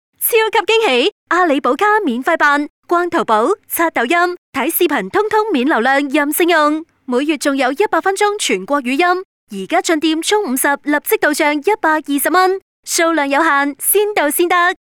女粤17_广告_手机通信_阿里宝卡_活力.mp3